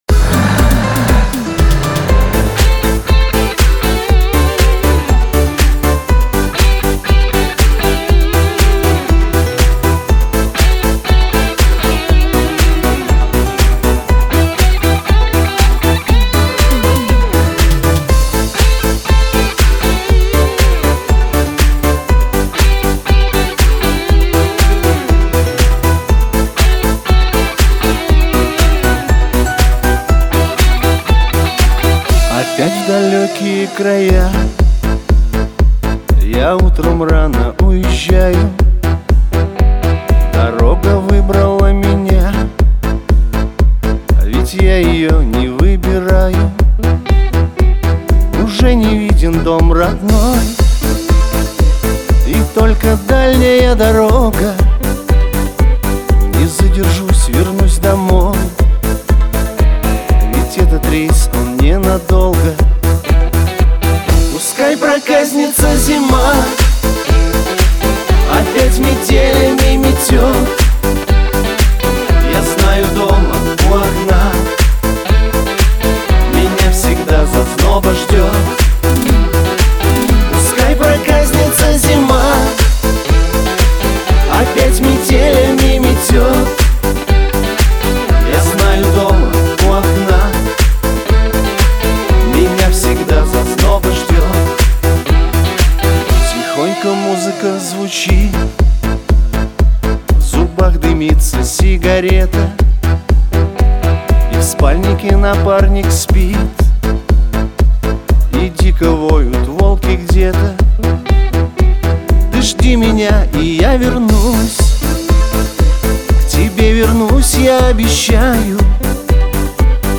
это проникновенная песня в жанре поп-рок